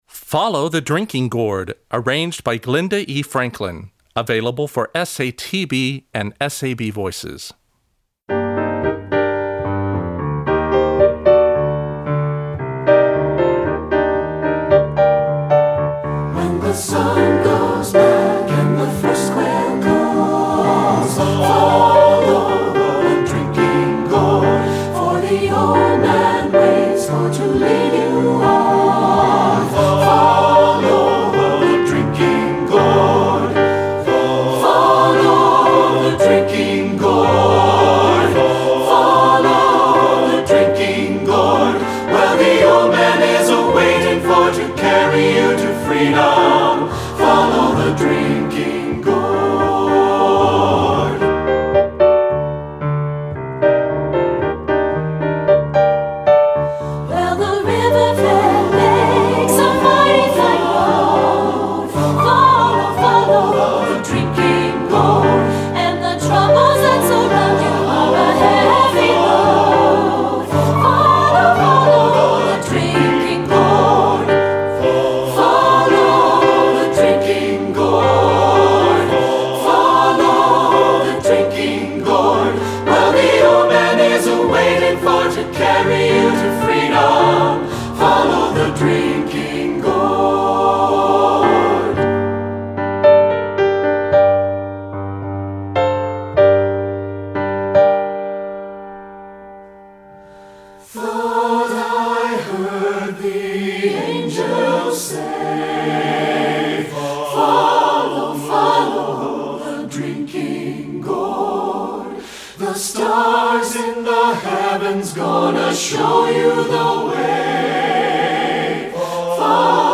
Composer: Spirituals
Voicing: SATB